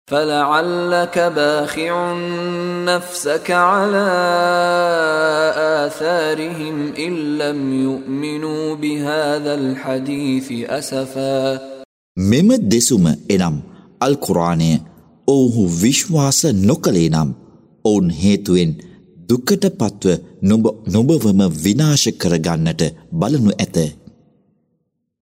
මිෂාරි බින් රෂීඩ් අල්-අෆාසිගේ පාරායනය සමඟින් පදයෙන් පදය වෙන් කරන ලද සූරා අල්-කහ්ෆ් හි අර්ථකථනය සිංහල හඬින්.